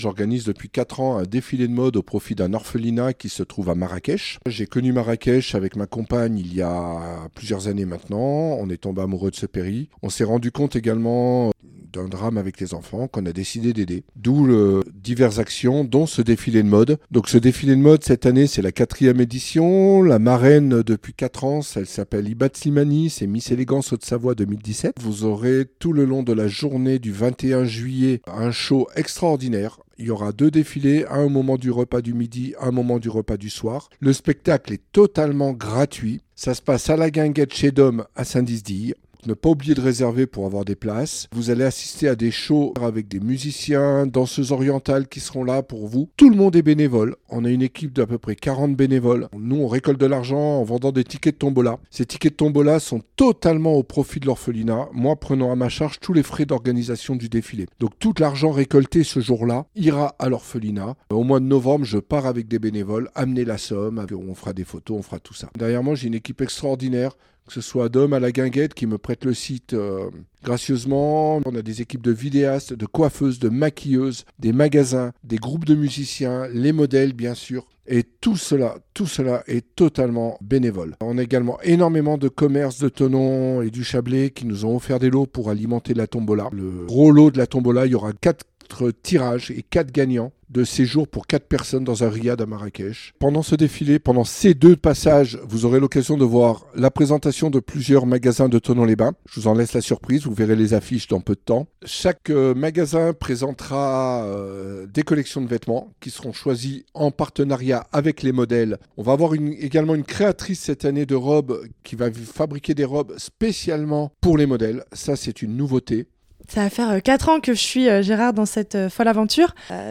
au micro de La Radio Plus.